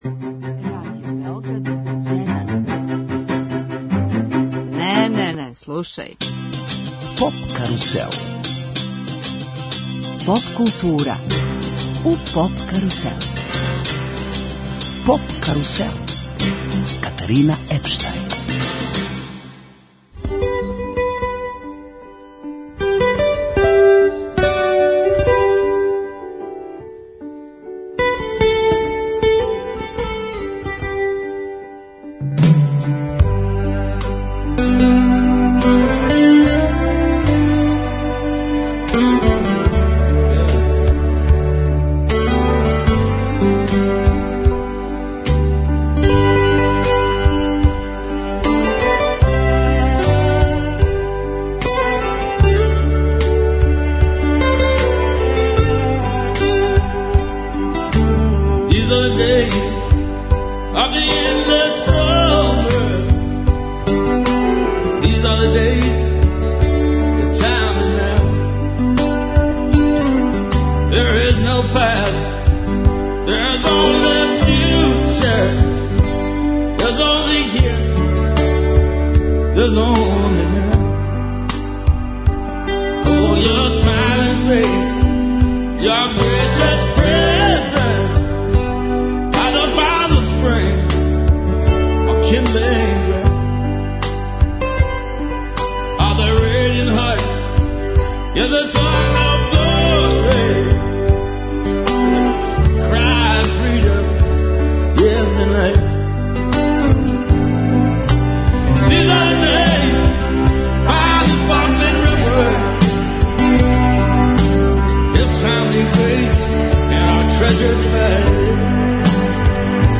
Гост емисије је певач и композитор Кики Лесендрић, поводом премијере новог сингла под именом ,,1981'.